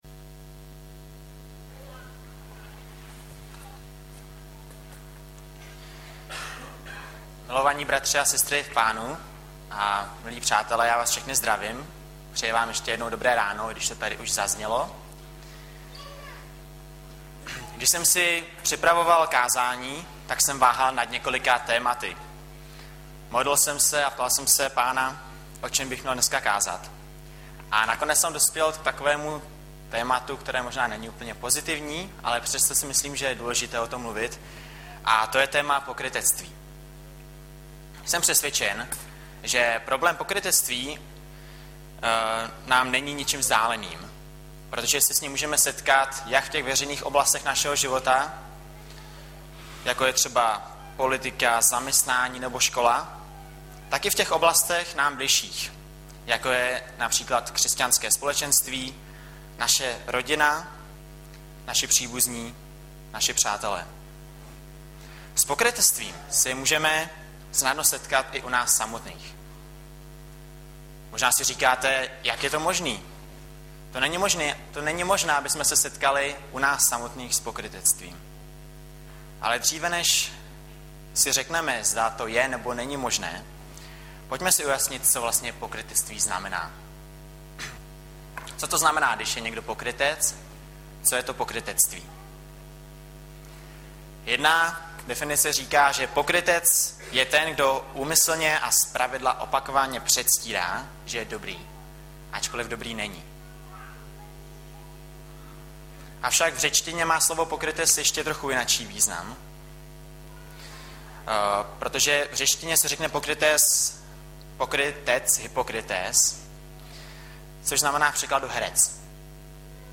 Hlavní nabídka Kázání Chvály Kalendář Knihovna Kontakt Pro přihlášené O nás Partneři Zpravodaj Přihlásit se Zavřít Jméno Heslo Pamatuj si mě  04.11.2012 - POKRYTECTVÍ - Mat 23,27 Audiozáznam kázání si můžete také uložit do PC na tomto odkazu.